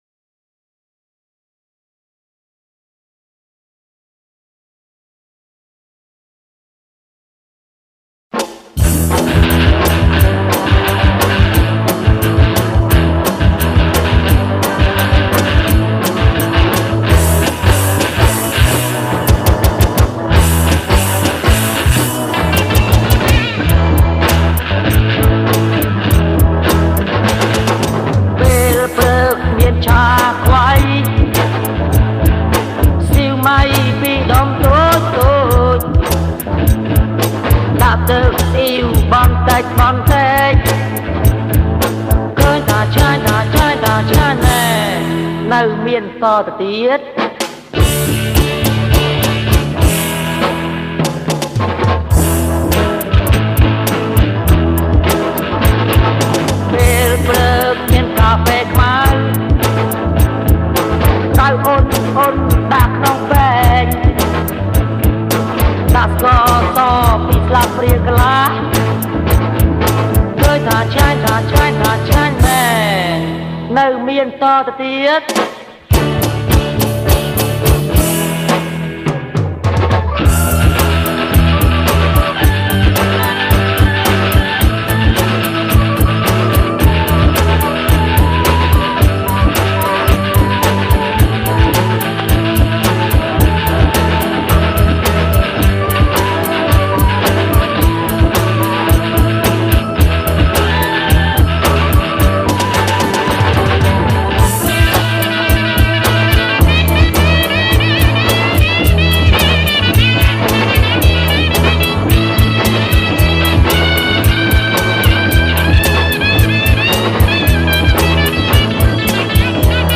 ប្រគំជាចង្វាក់ Soul